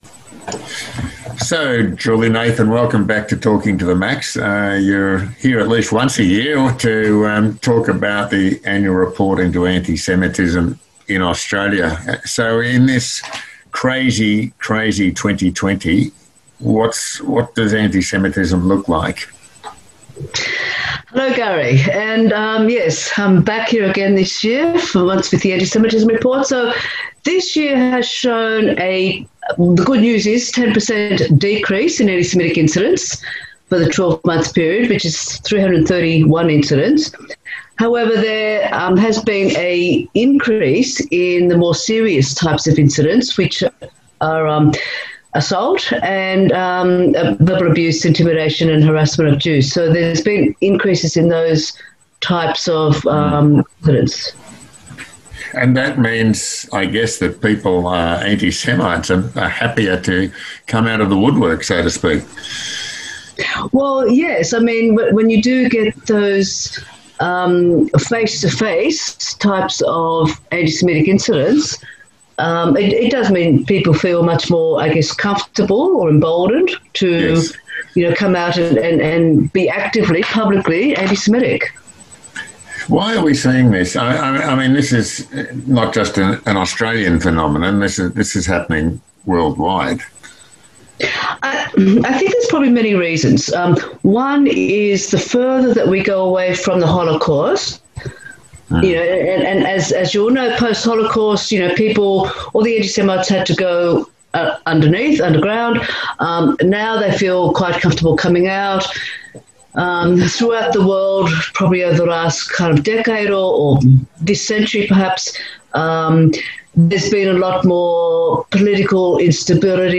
Interview: ECAJ 2020 Antisemitism Report on J‑Air - ECAJ